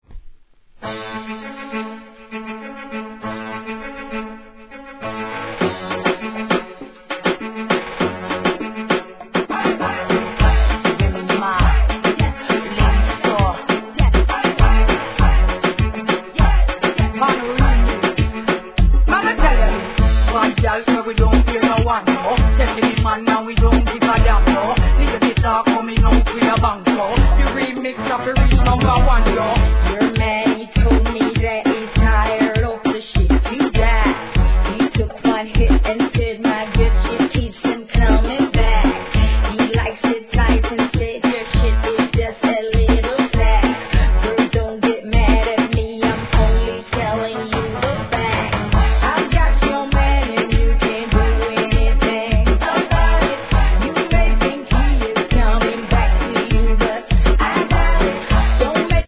REGGAETON鉄盤!!